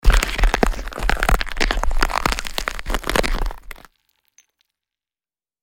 Звуки заморозки